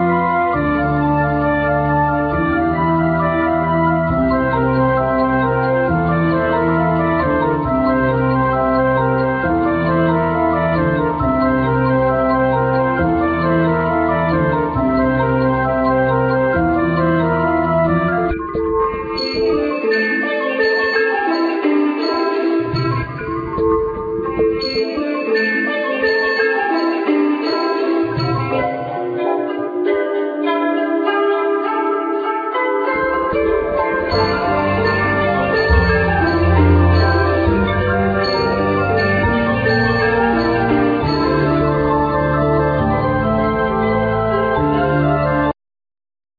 Bandneon
Trombone
Baryton saxophone
Contrabass
Tabla
Cello